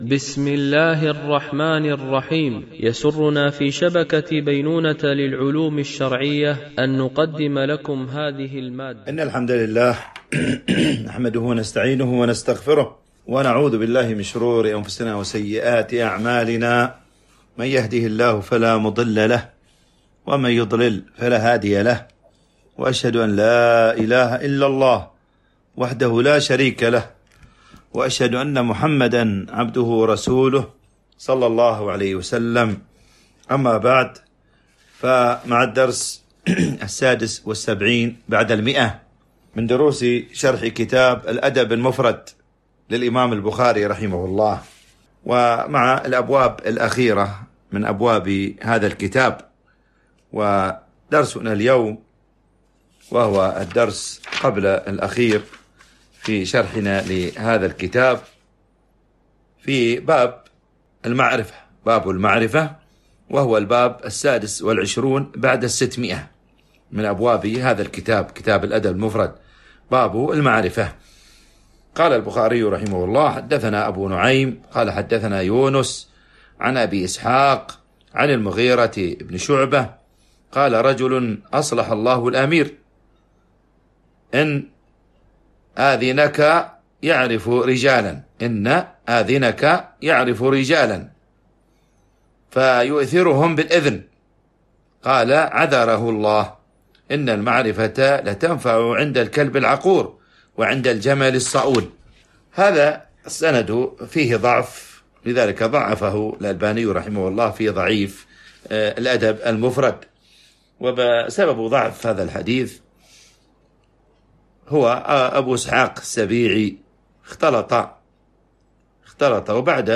شرح الأدب المفرد للبخاري ـ الدرس 176 ( الحديث 1296- 1308)